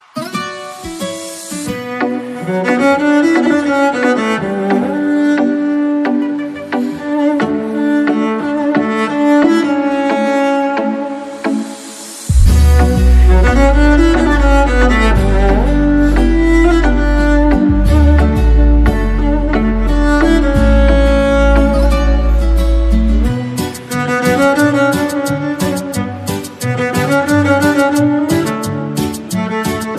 Latin pop song
Ringtone